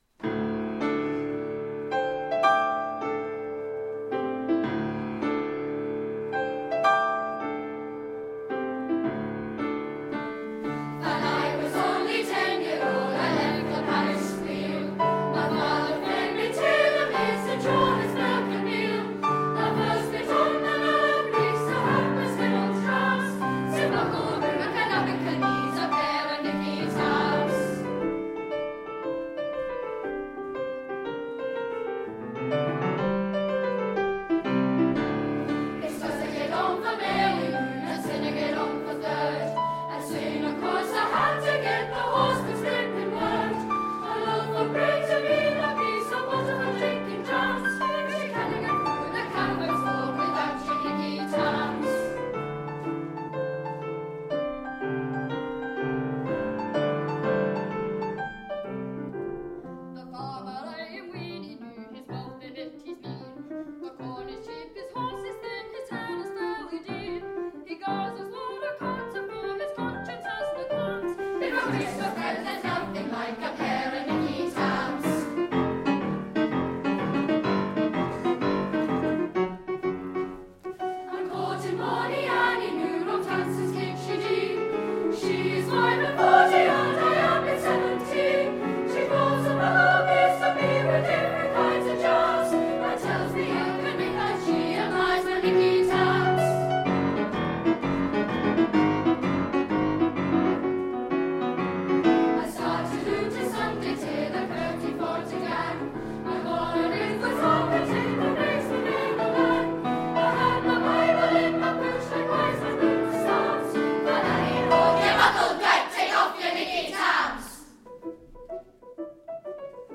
for children’s choir and piano